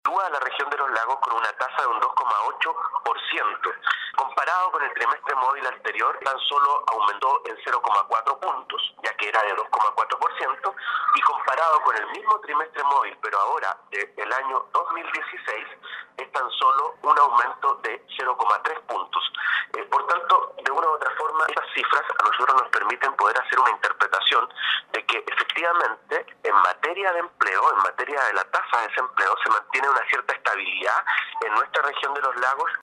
A nivel provincial, la Provincia de Osorno registró una tasa de 2,2%, explicó a radio Sago el seremi del Trabajo y Previsión Social, Claudio Villanueva.